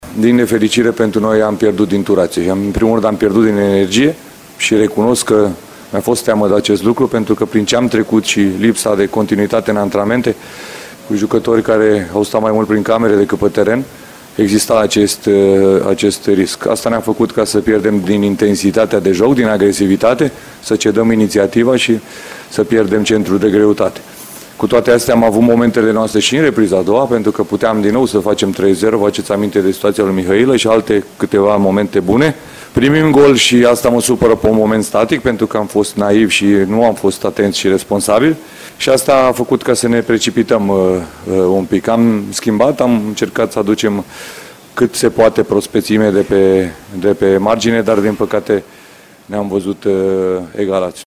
Selecţionerul Edward Iordănescu încearcă să vadă părțile pozitive ale acestui meci și continuă cu expunerea motivelor pentru care naționala mare nu a obținut chiar victoria, pe care părea că o are în mână: